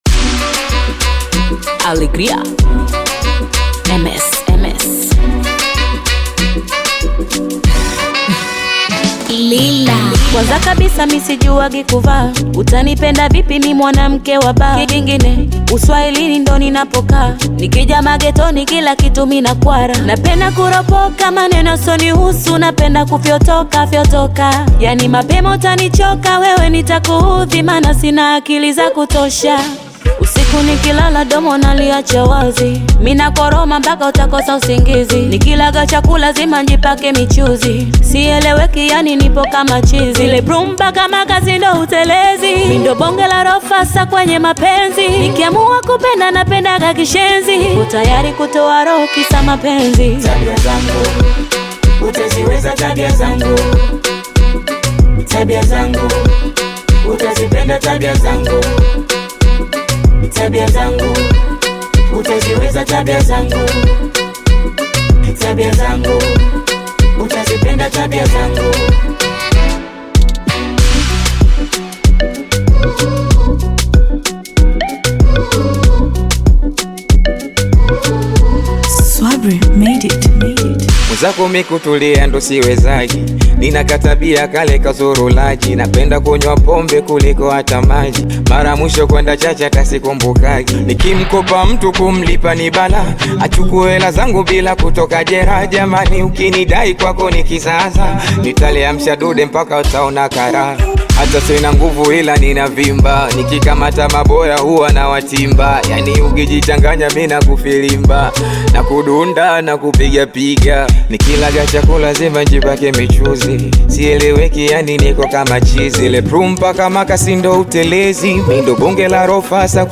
Tanzanian bongo flava artist
African Music